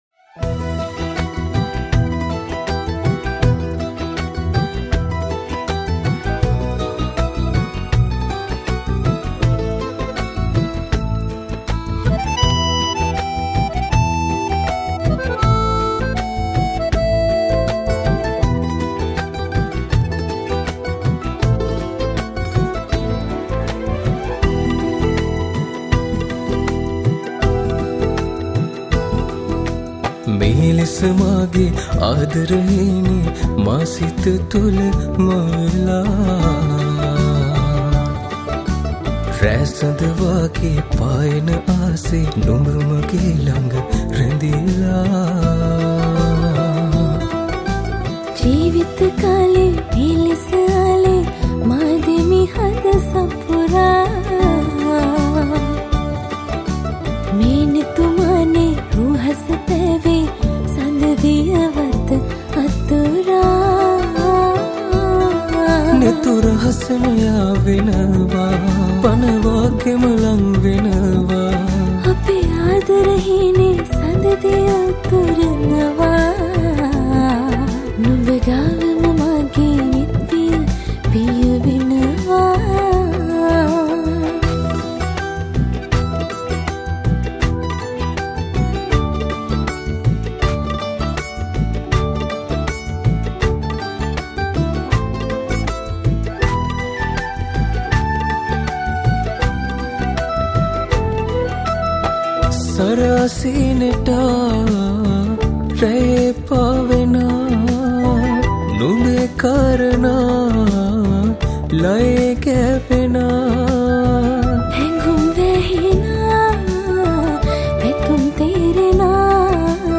Teledrama Song